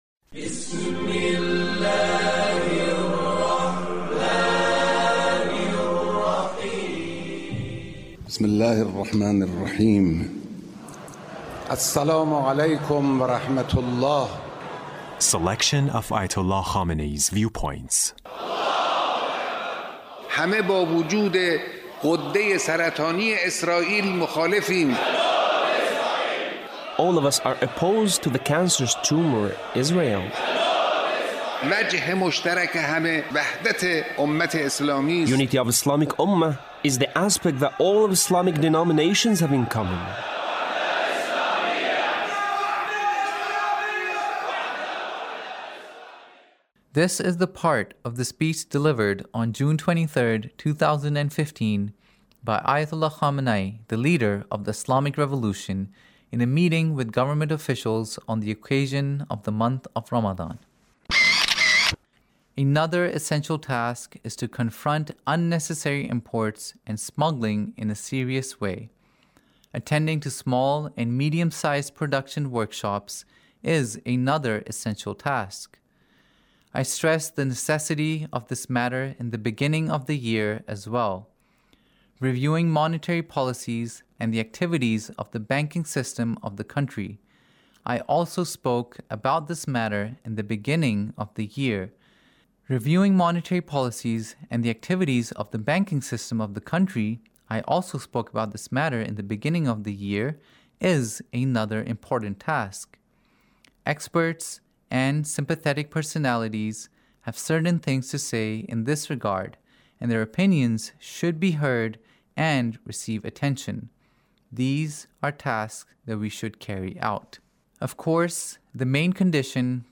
Leader's Speech On The Month of Ramadhan in a Meeting with the Government Officials